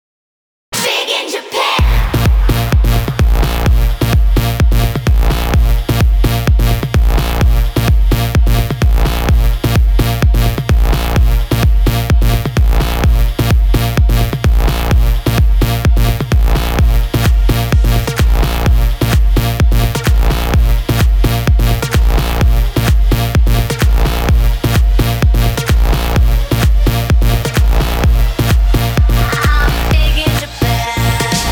club музыка